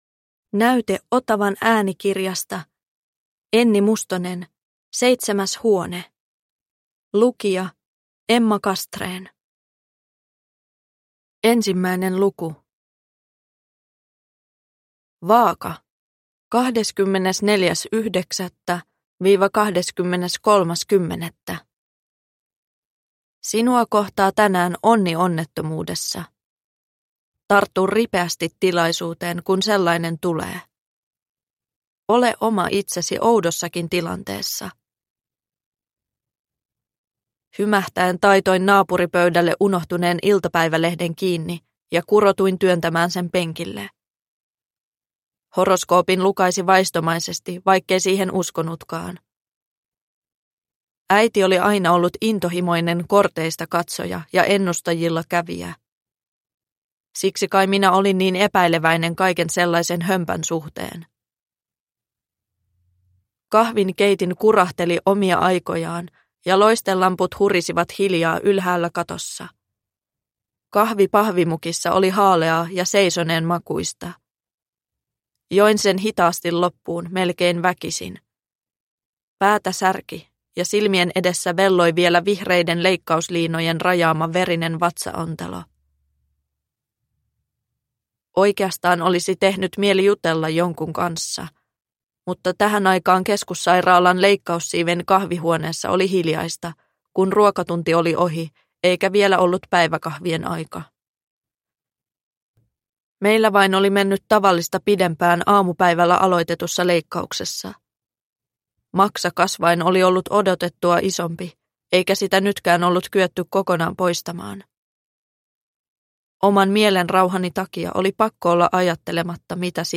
Seitsemäs huone – Ljudbok – Laddas ner